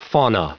Prononciation du mot fauna en anglais (fichier audio)
Prononciation du mot : fauna